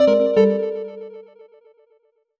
jingle_chime_24_negative.wav